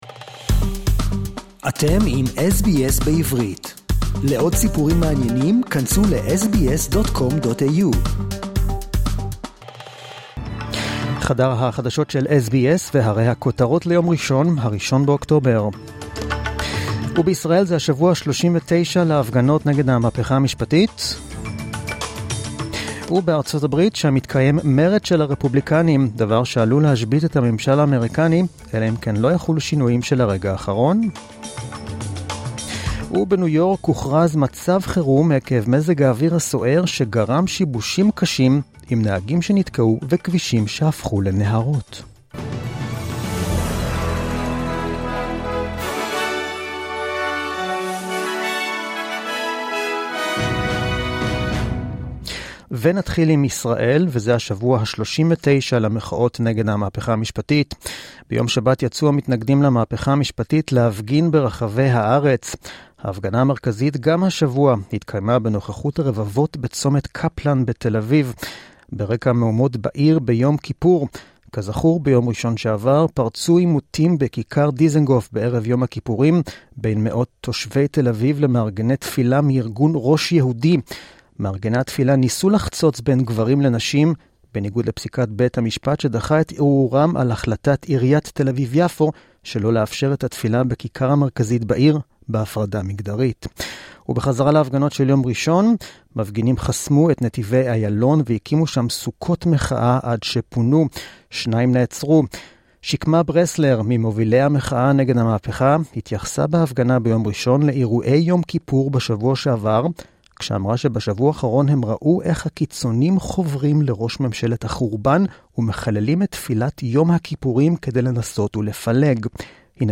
The latest news in Hebrew as heard on the SBS Hebrew program